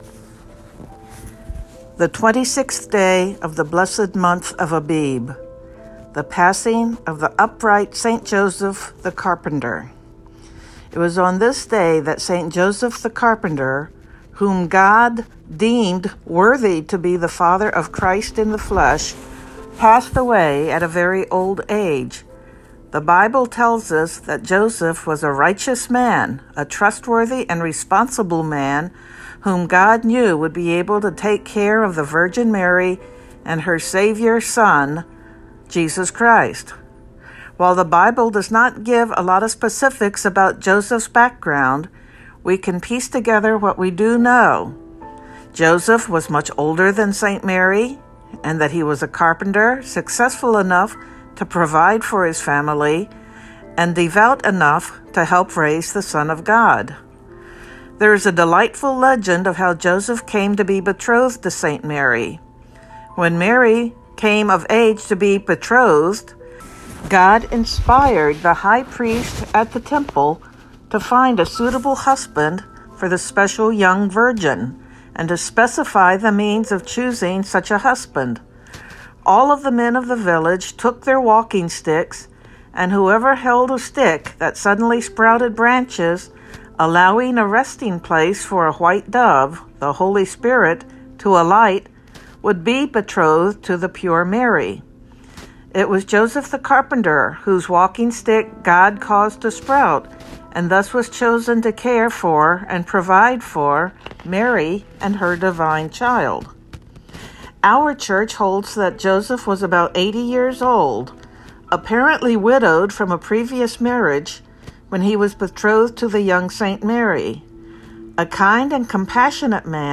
Synaxarium readings for the 26th day of the month of Abib